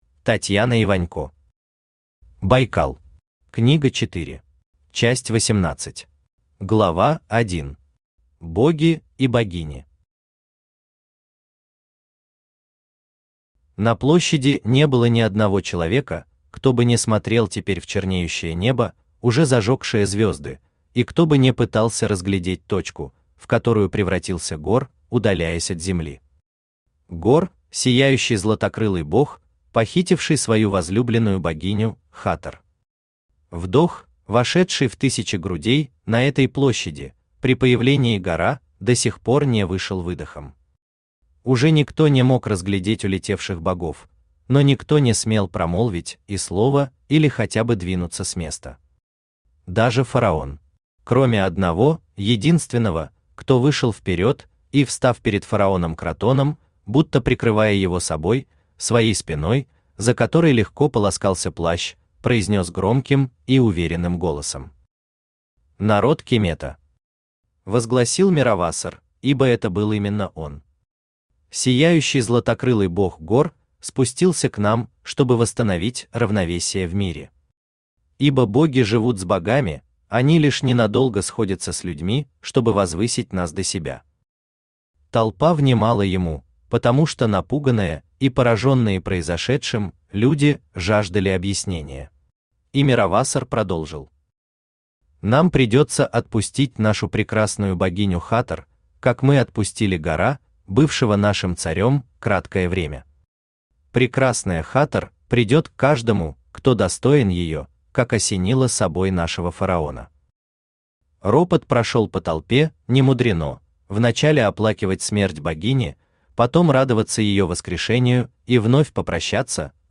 Аудиокнига Байкал. Книга 4 | Библиотека аудиокниг
Книга 4 Автор Татьяна Вячеславовна Иванько Читает аудиокнигу Авточтец ЛитРес.